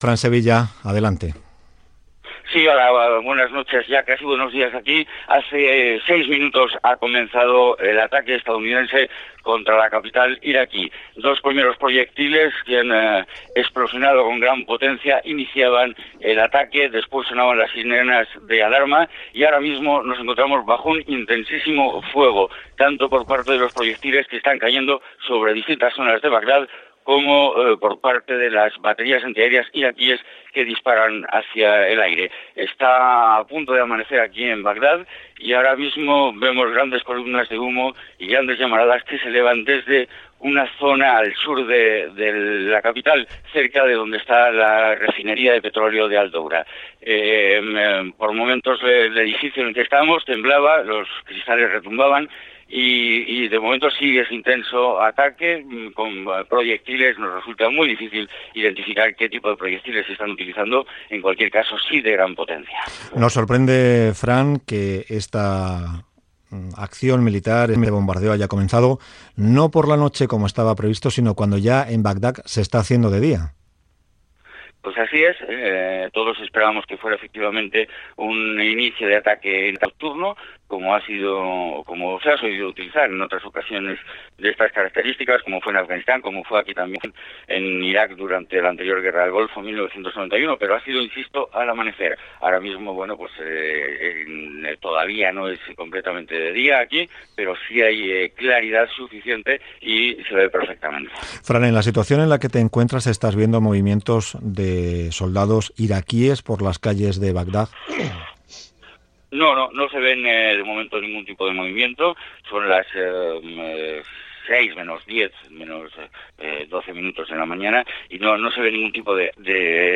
bafdd15bc16eaf45e5fd2fcd7aec3041c084bc91.mp3 Títol Radio Nacional de España Emissora Radio Nacional de España Barcelona Cadena RNE Titularitat Pública estatal Descripció Informació des de Bagdad de l'inici de la Guerra de l'Iraq, a la matinada. Connexió amb Washington i amb les fronteres de Kuwait i Turquia Gènere radiofònic Informatiu